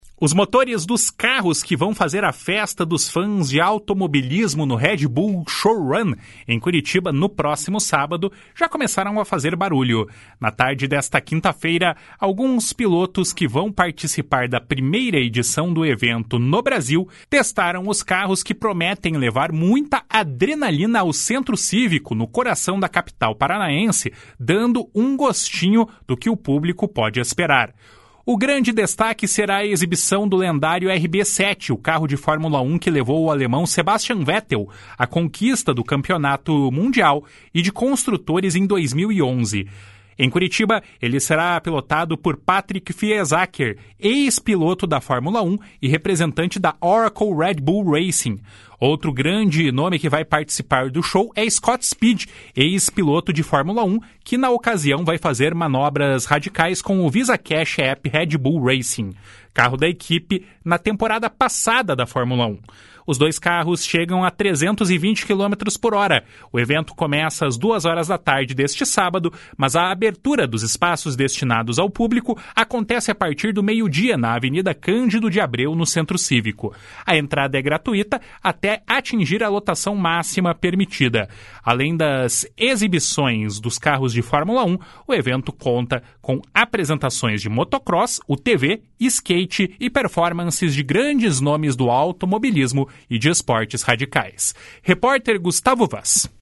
Os motores dos carros que vão fazer a festa dos fãs de automobilismo no Red Bull Showrun, em Curitiba, no próximo sábado, já começaram a fazer barulho. Na tarde desta quinta-feira, alguns pilotos que vão participar da primeira edição do evento no Brasil testaram os carros que prometem levar muita adrenalina ao Centro Cívico, no coração da capital paranaense, dando um gostinho do que o público pode esperar.